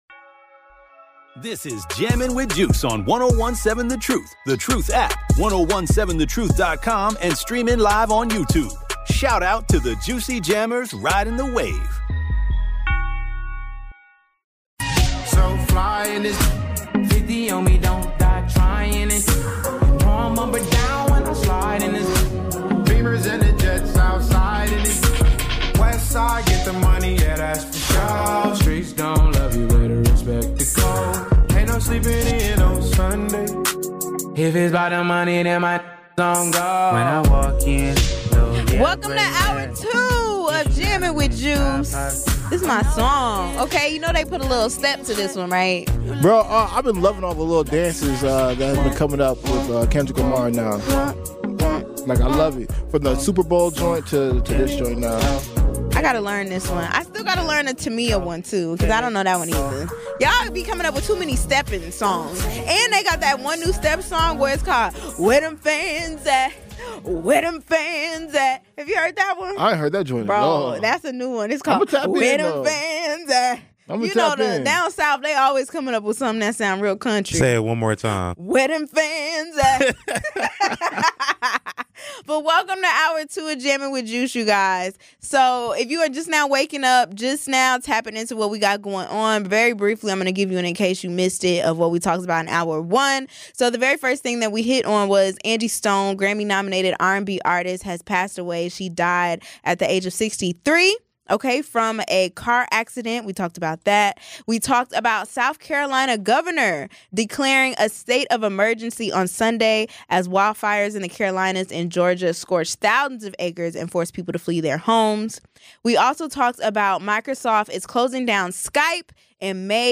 This time, things get personal as the crew tackles a powerful question: Who really sets the beauty standard for women? Join us for a thought-provoking conversation that blends politics, mysteries, and societal pressures.